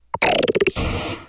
Grito de Clawitzer.ogg
Grito_de_Clawitzer.ogg.mp3